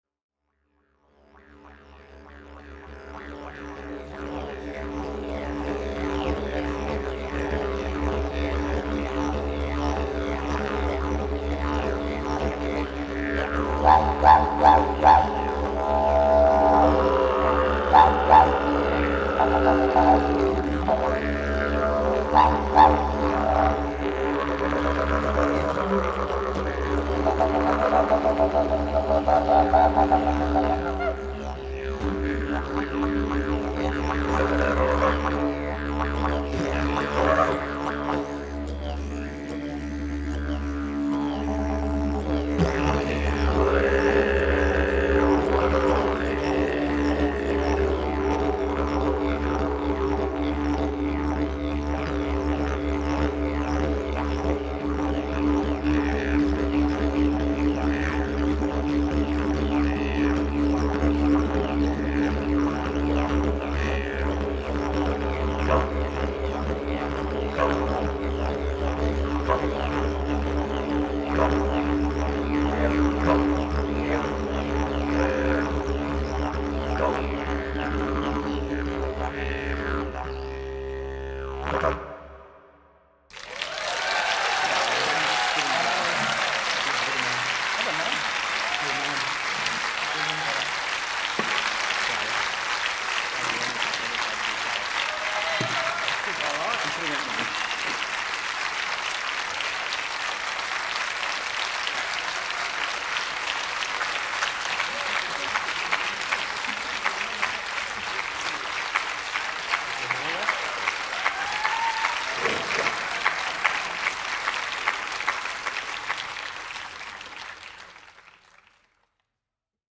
This MP3 file represents the EIGHT (8) Kryon channellings given at the Kryon Gaia Global Consciousness Retreat held in ULURU Australia.
Evening channelling.mp3